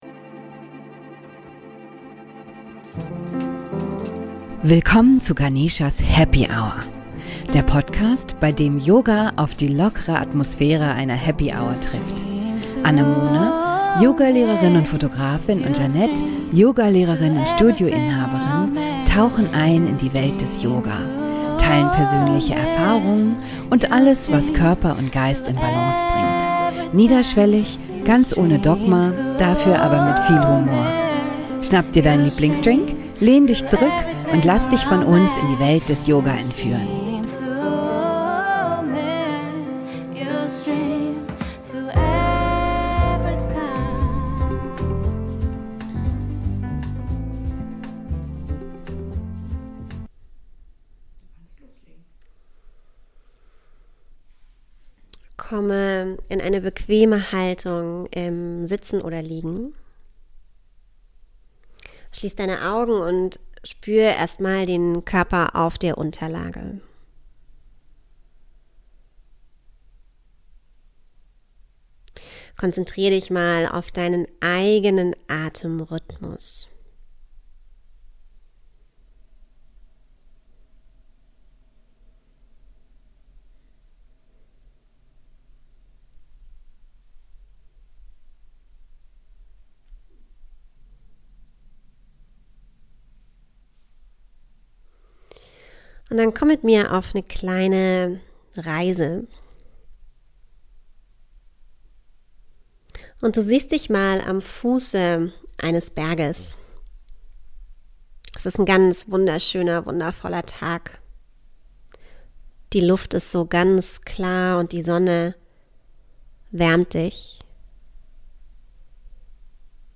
Atemübung: Atemreise auf den Glücksberg